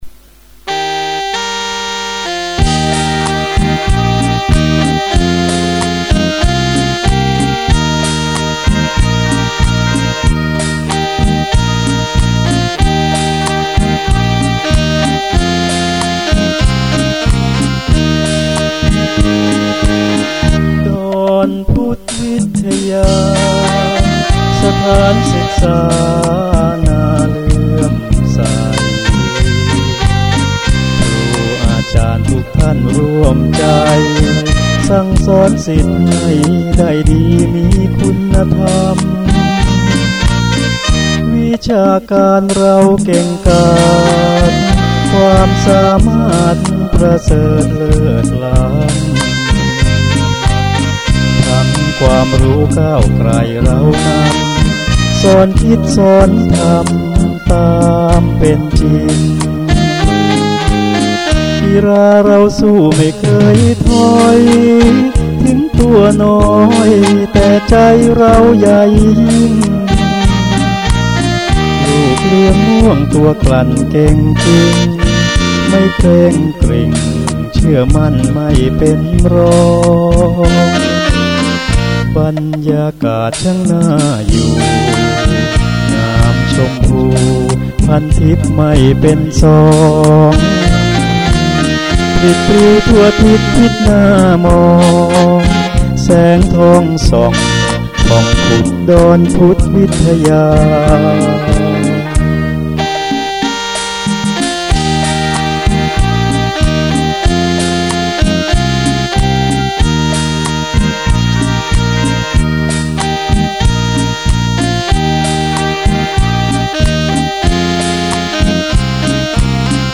จังหวะ   บีกิน